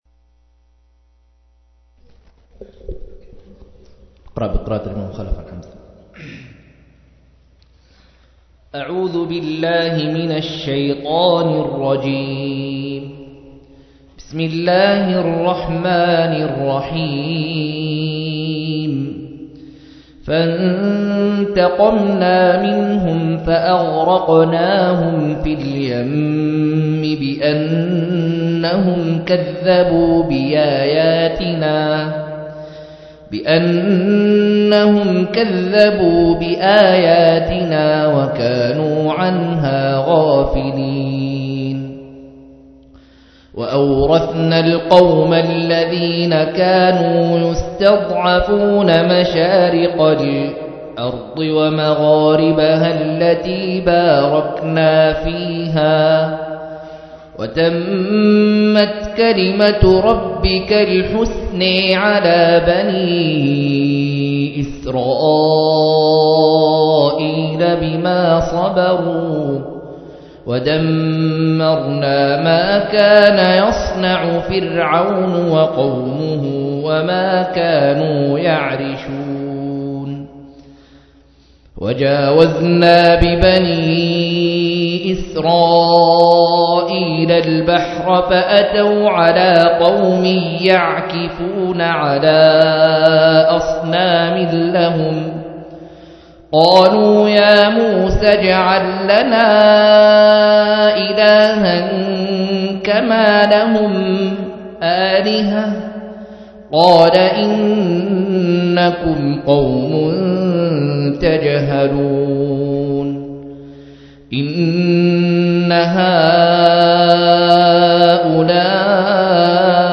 155- عمدة التفسير عن الحافظ ابن كثير رحمه الله للعلامة أحمد شاكر رحمه الله – قراءة وتعليق –